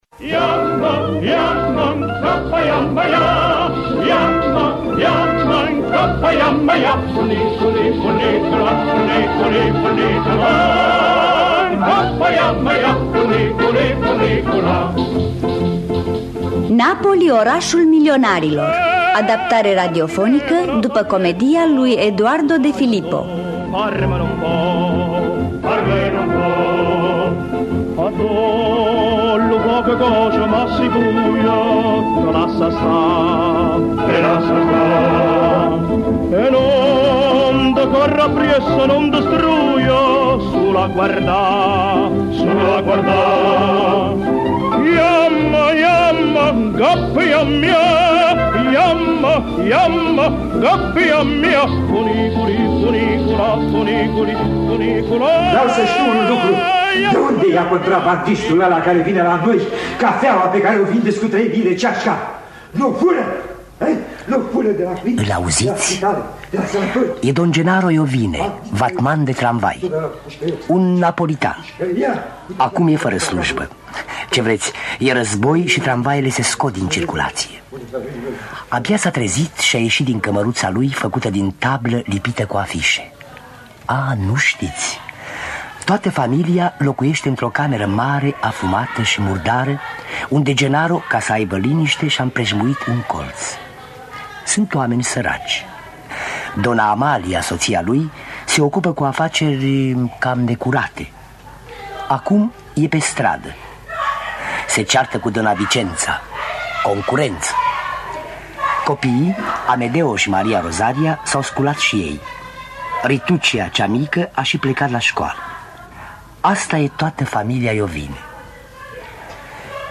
Cu Ştefan Mihăilescu Brăila în rolul principal.